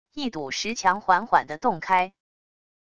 一堵石墙缓缓的洞开wav音频